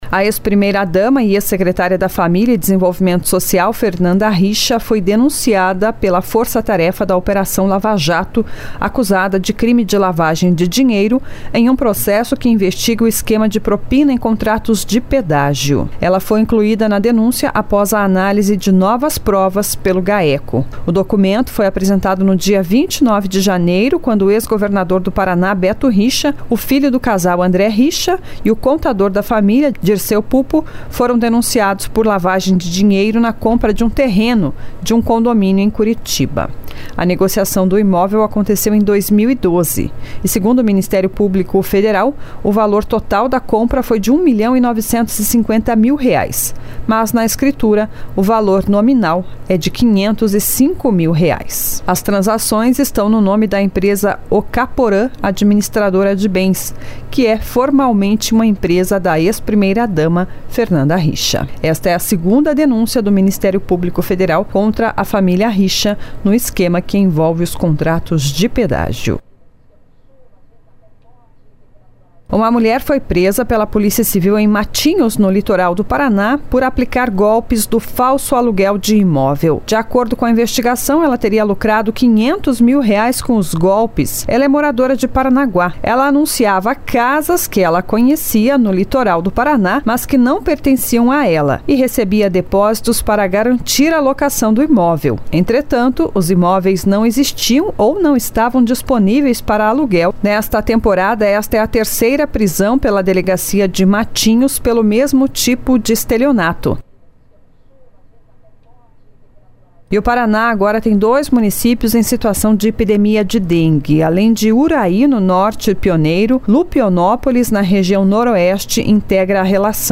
Giro de Notícias SEM TRILHA